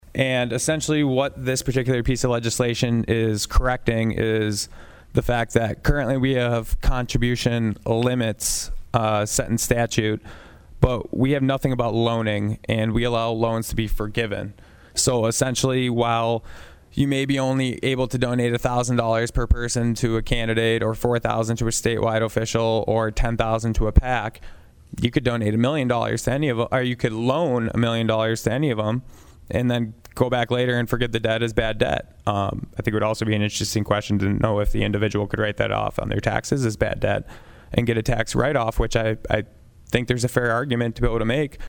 PIERRE, S.D.(HubCityRadio)- District 1 Senator Michael Rohl presented SB12 to the South Dakota Senate State Affairs Committee Wednesday.
Senator Rohl describe what the bill does.